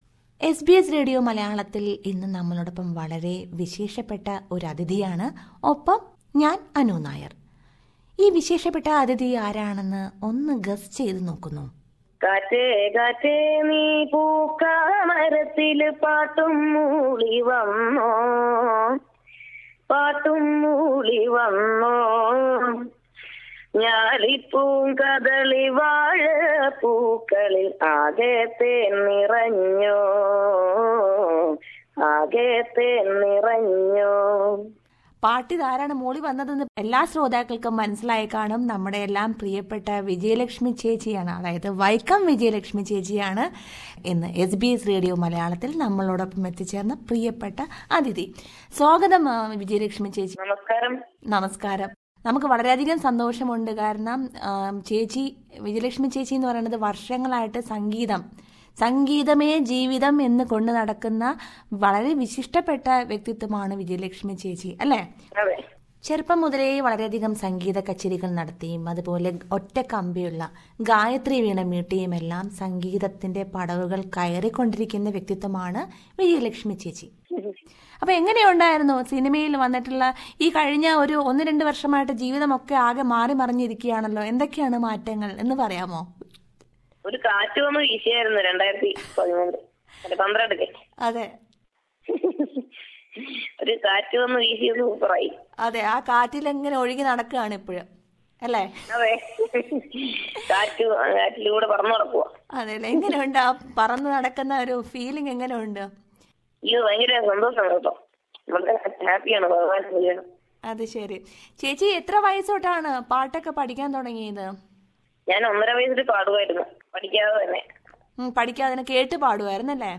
Now you don't have to wait to hear from them till they are here...SBS Malayalam takes initiative in interviewing celebrities from India. Listen to such an interview of singer Vaikom Vijayalakshmi who opens up about her entry into music industry and her songs...